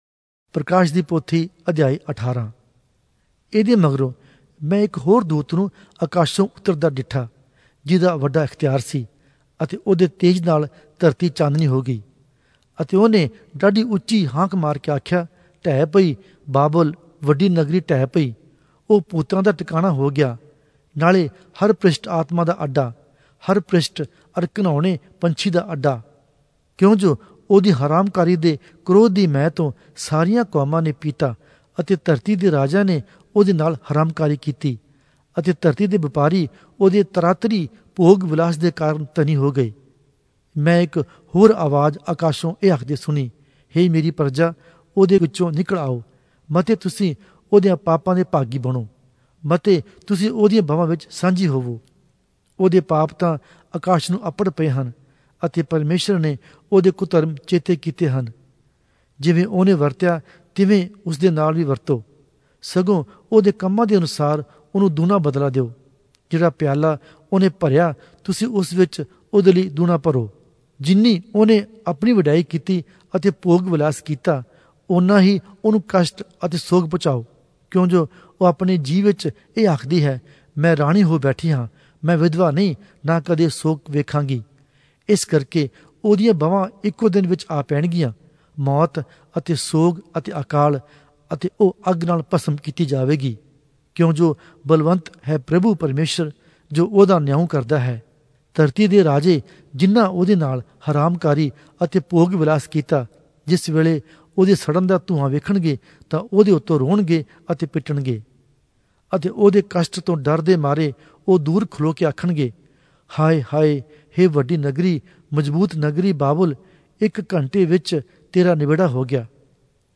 Oriya Audio Bible - Revelation 20 in Urv bible version